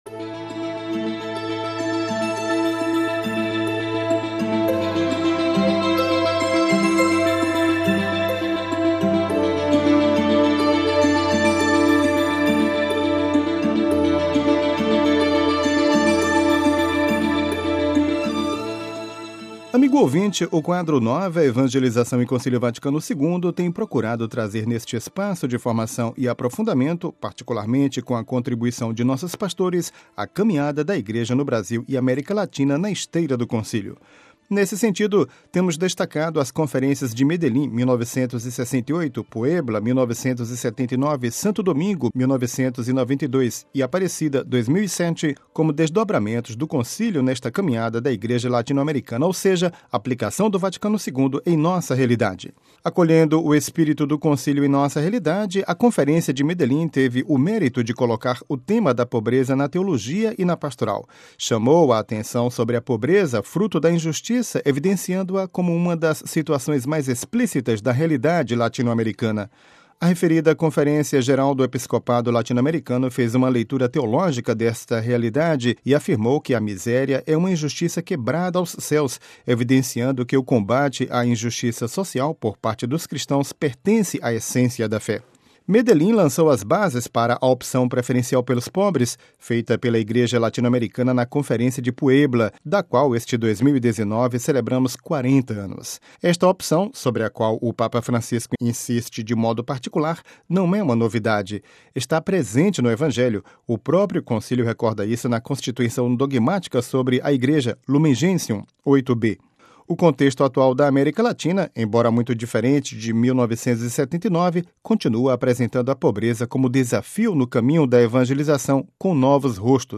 Na edição de hoje Dom Petrini continua suas considerações afirmando-nos, entre outros, que a opção preferencial pelo pobres procura condições para construir uma sociedade mais igualitária, mais justa, mais fraterna.